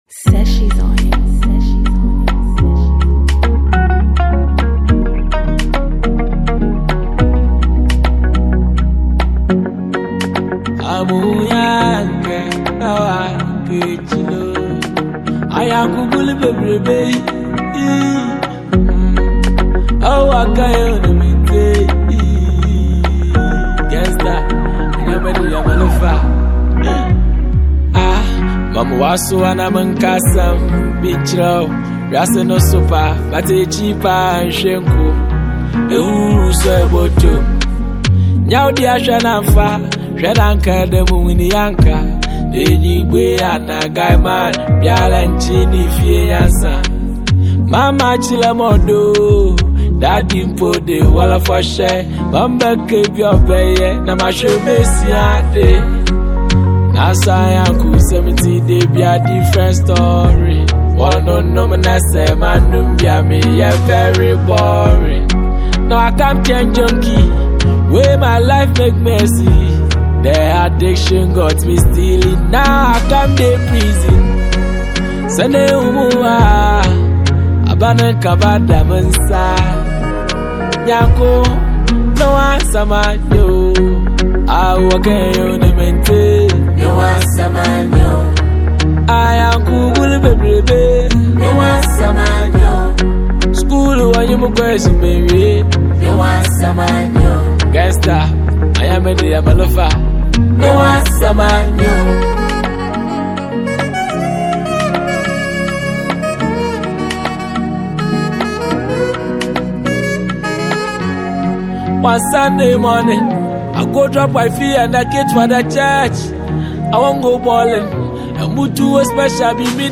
Fante Rap